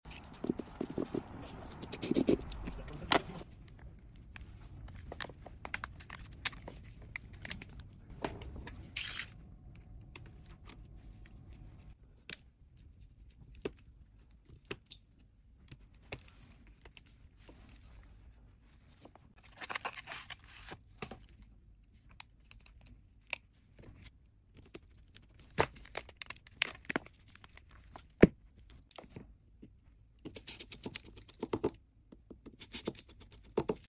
🔧📱 iPhone XS Repair with sound effects free download
🔧📱 iPhone XS Repair with ASMR Vibes!
😌🔋 From crisp clicks to satisfying screws, this repair is pure tech ASMR bliss! 🛠🔊 🎥 Sit back, relax, and let the soothing sounds of precision take over.